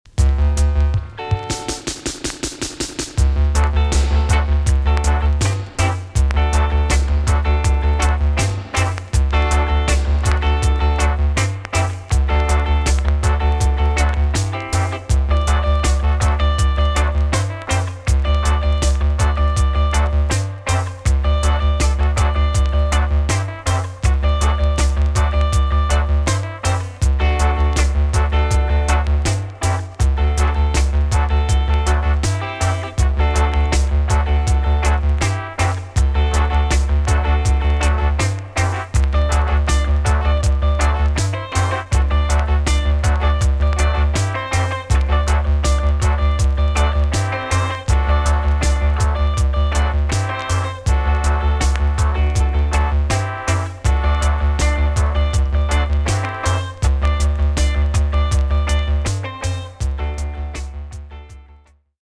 TOP >80'S 90'S DANCEHALL
B.SIDE Version
EX-~VG+ 少し軽いチリノイズが入りますが良好です。
WICKED OUT OF KEY STYLE!!